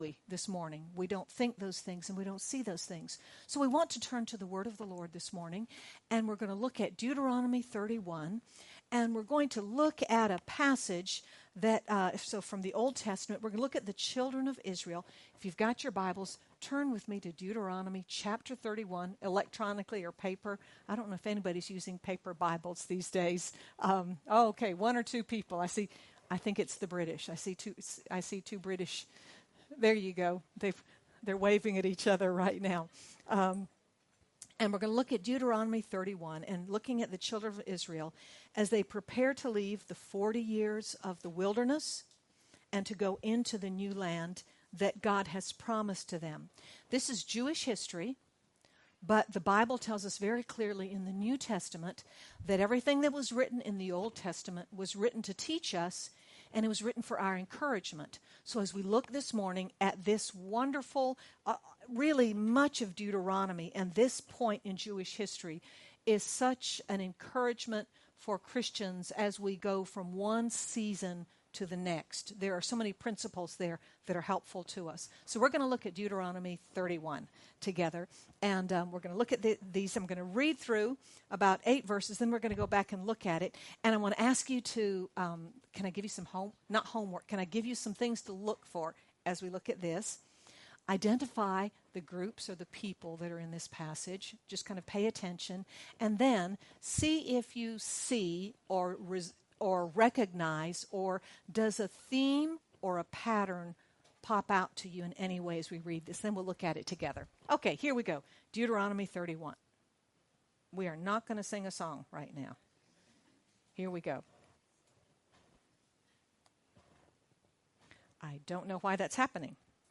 Whatever we face, our one sure foundation is that God Himself, personally, is with us. Sermon by